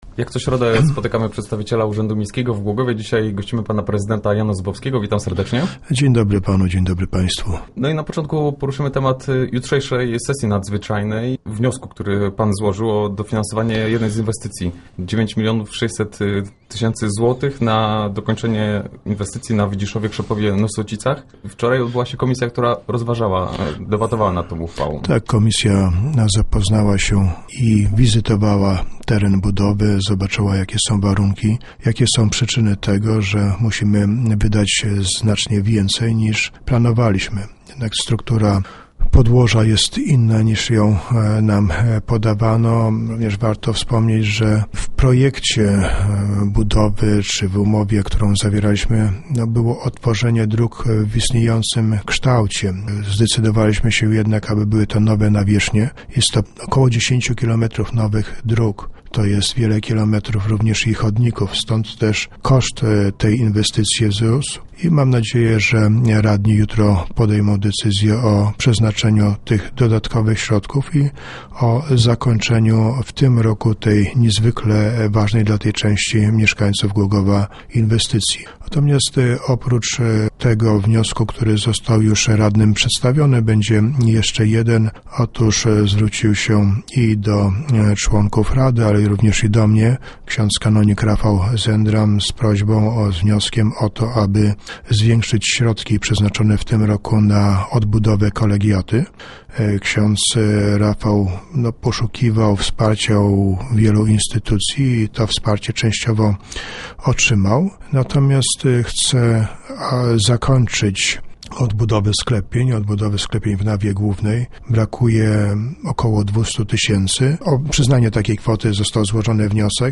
Między innymi o tym rozmawiamy z prezydentem Janem Zubowskim.